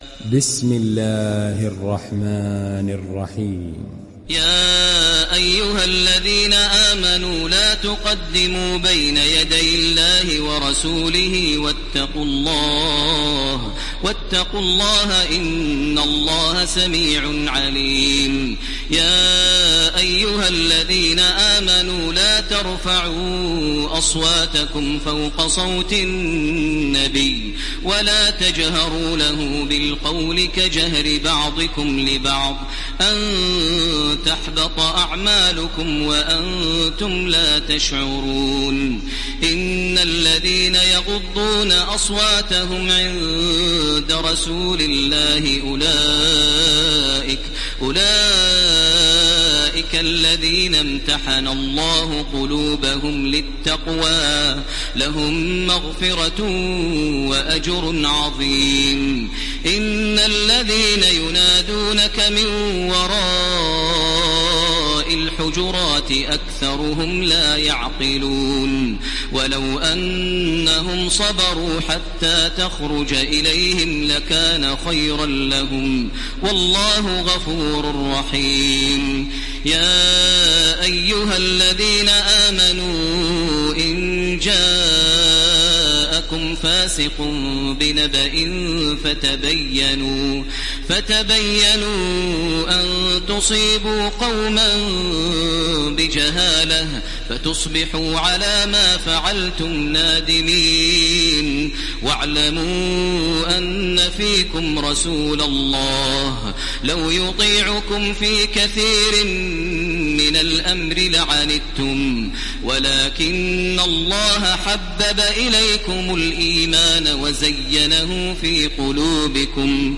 Download Surat Al Hujurat Taraweeh Makkah 1430